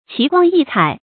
奇光异彩 qí guāng yì cǎi
奇光异彩发音
成语注音 ㄑㄧˊ ㄍㄨㄤ ㄧˋ ㄘㄞˇ